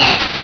Cri de Chartor dans Pokémon Rubis et Saphir.